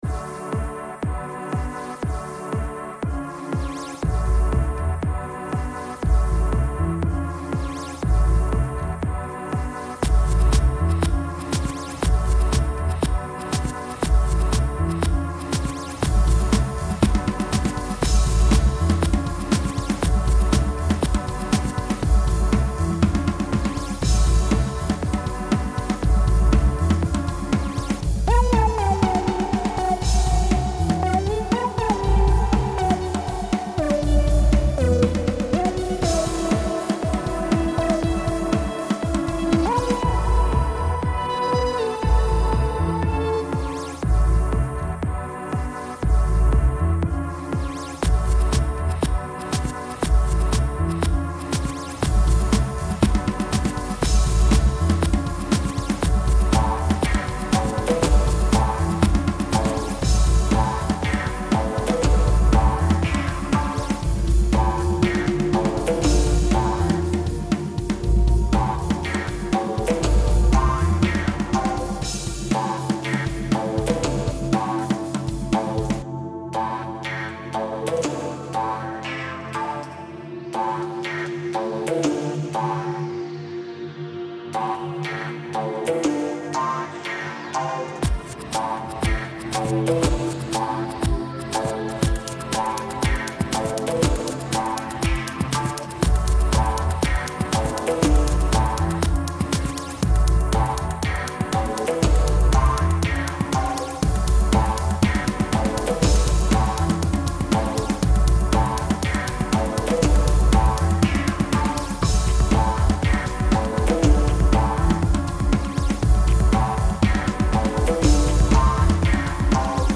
Description: music,vibe,digital,analog